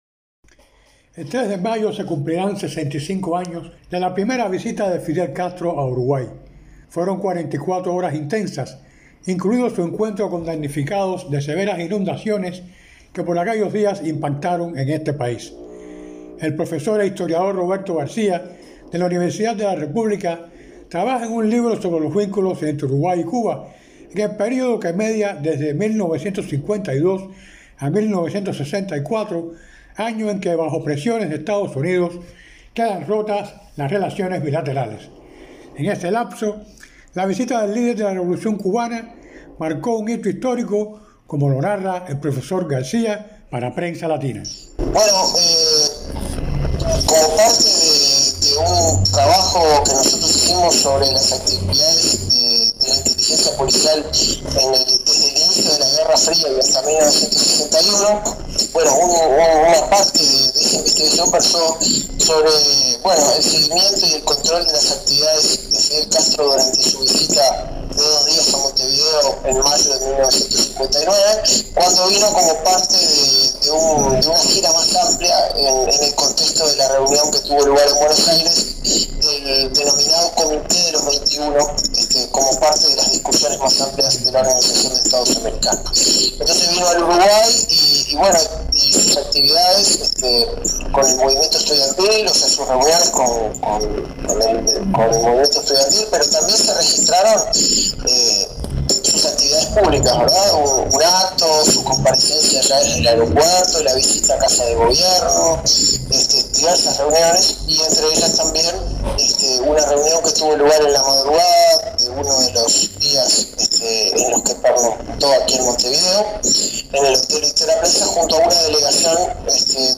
historiador y profesor uruguayo